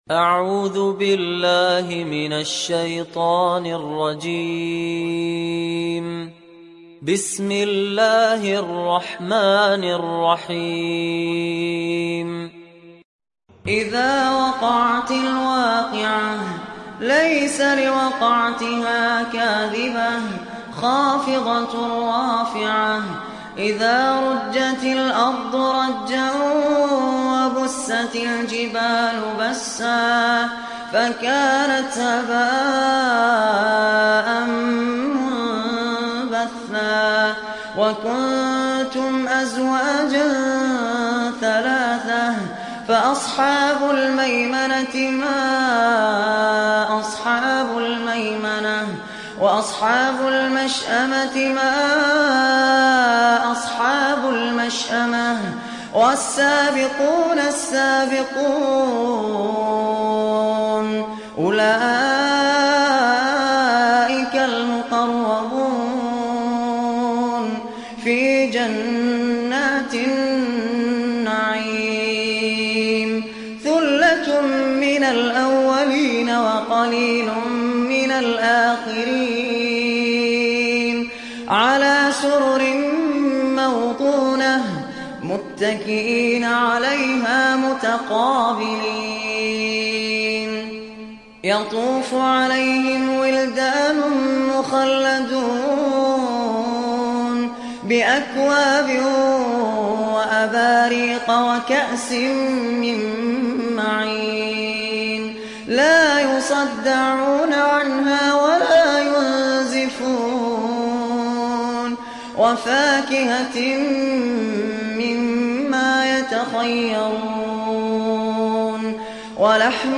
Vakia Suresi İndir mp3 Fahad Alkandari Riwayat Hafs an Asim, Kurani indirin ve mp3 tam doğrudan bağlantılar dinle